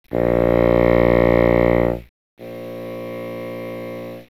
The were calculated assuming the listener and the bassoon were in a room measuring 10m x 10m x 25m.
In each case, the reverberant sound was mixed from six reflections.
The direct and reverberant signals from the bassoon, 5m away, then 15m away